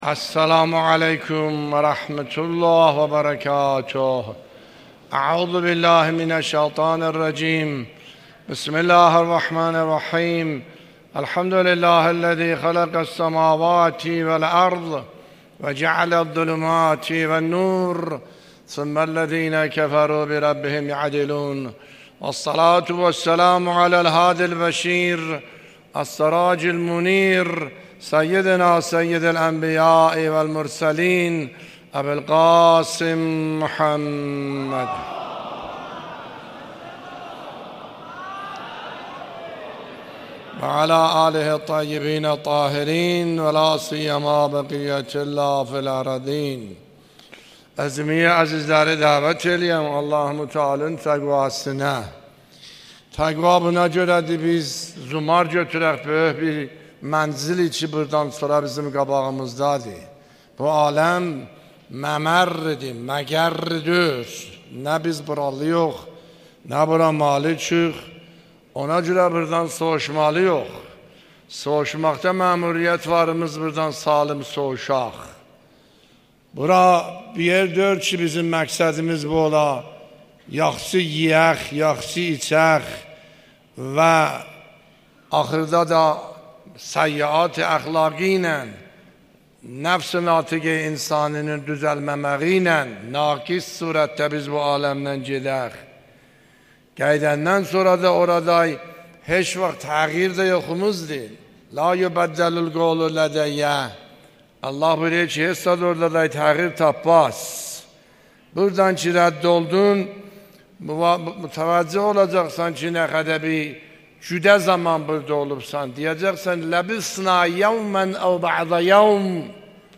خطبه‌ های نماز جمعه اردبیل | آیت الله عاملی (12 اسفند 1401) + متن | ضیاءالصالحین